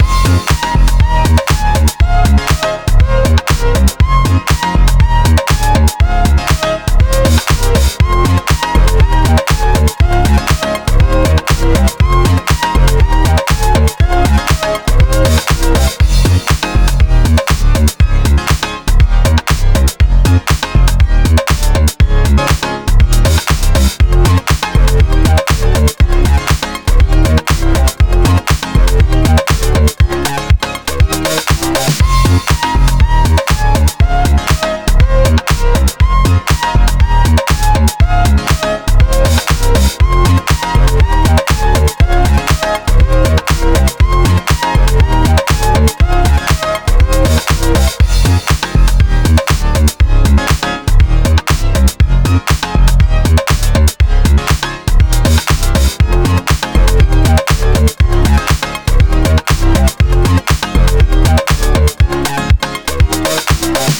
120 BPM